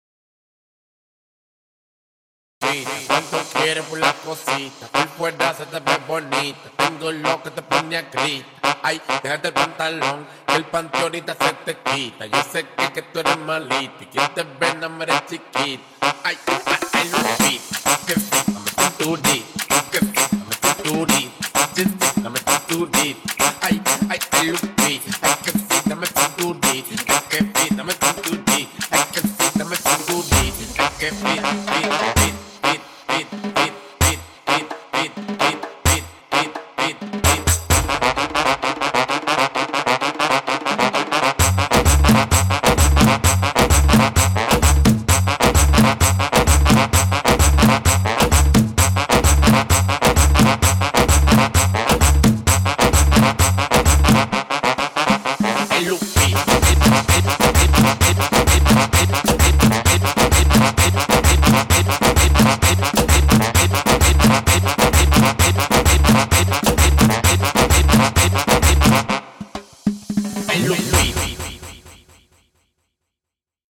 Guaracha